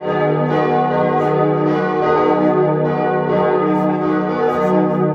Kleines Geläut
Hören Sie ein Teilgeläut unseres Kirchturms.
Pauluskirche-Gelaeut.mp3